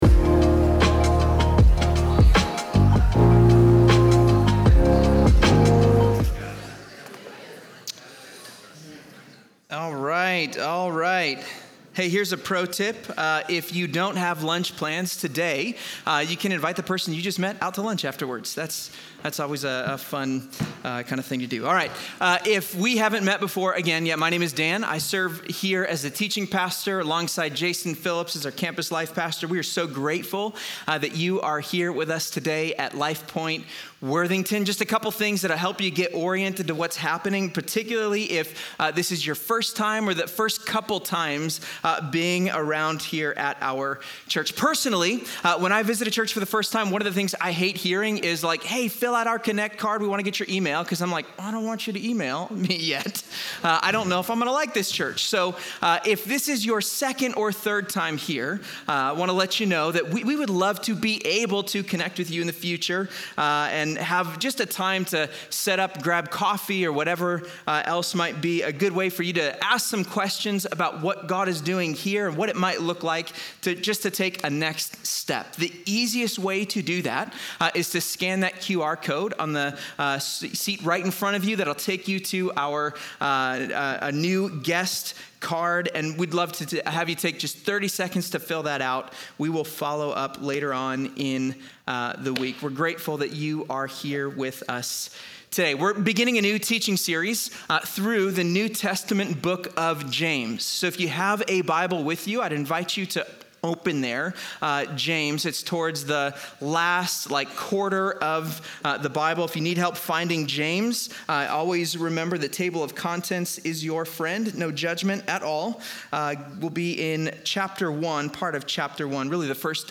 In this sermon, the speaker unpacks James 1:19–27 to show how allegiance to Jesus isn’t just a private belief—it’s a lived commitment.